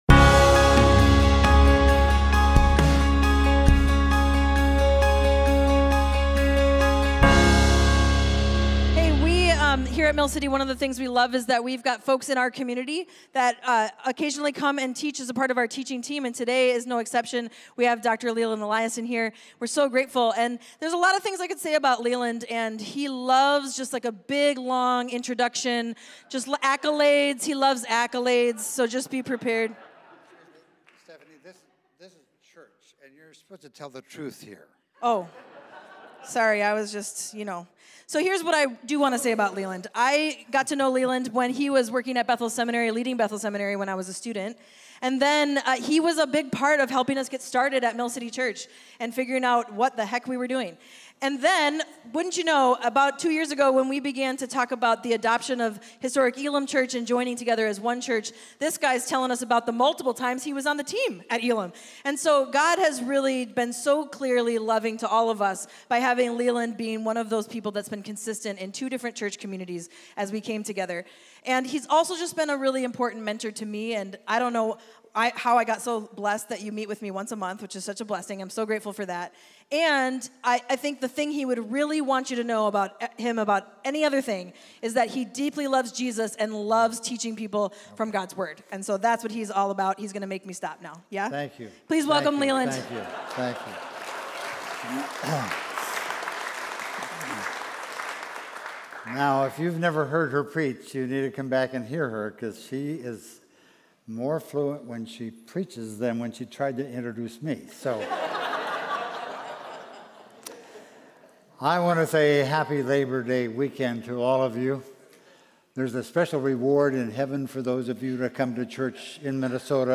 Mill City Church Sermons Authority in the Spirit: Do Not Despise Small Beginnings Sep 02 2024 | 00:27:19 1x 00:00 / 00:27:19 Subscribe Share RSS Feed Share Link Embed